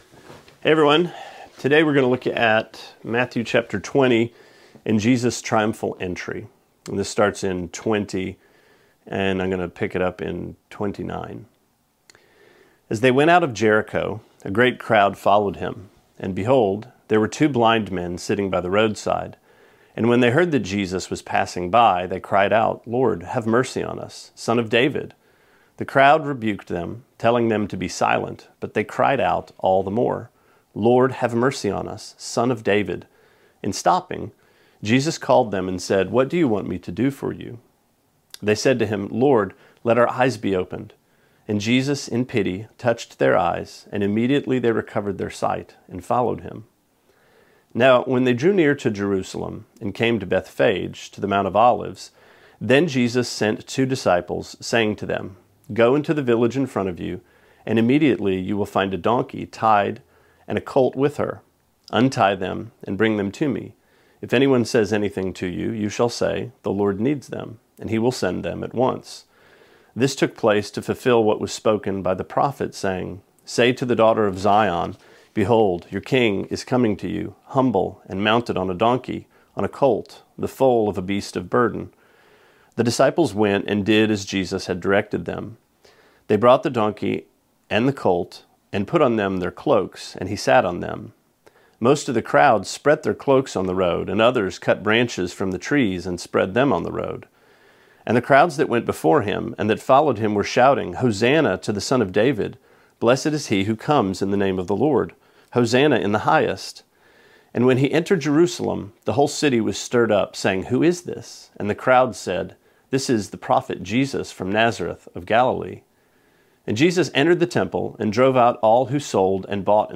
Sermonette 4/5: Matthew 20:29-21:17: The Return of the King